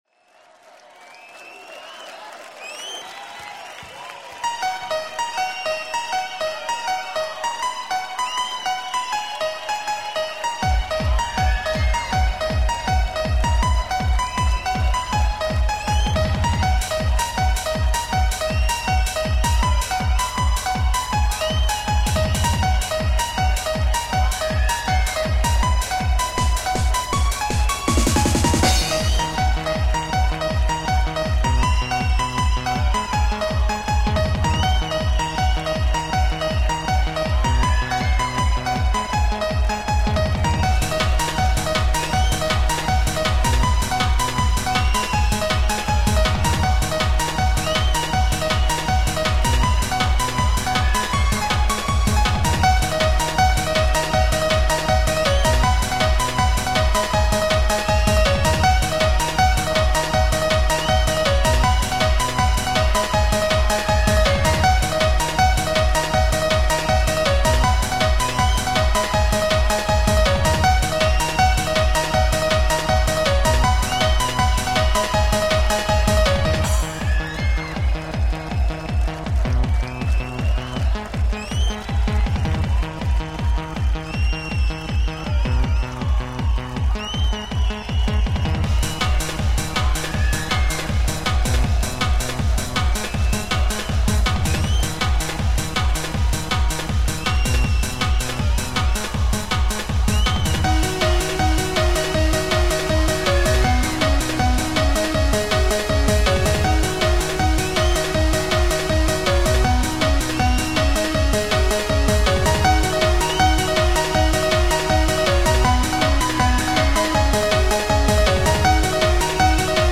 piano club mix
• Jakość: 44kHz, Stereo